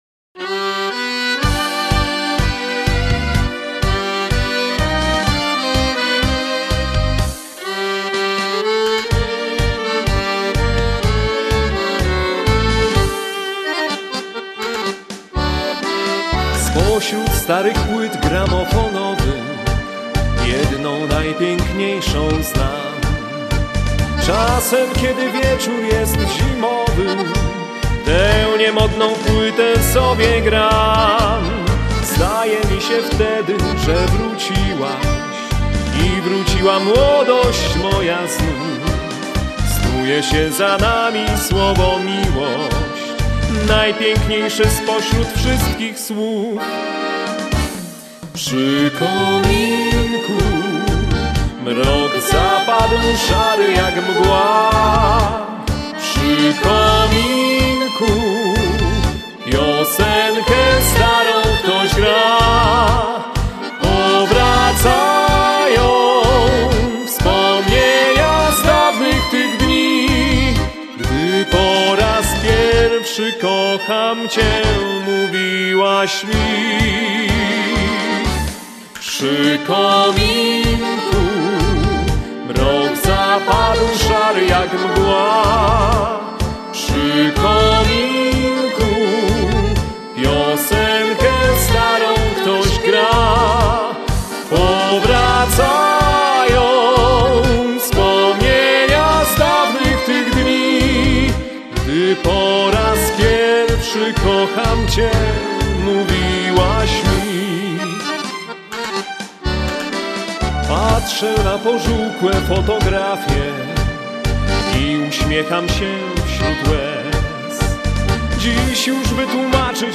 Современное исполнение популярного польского танго.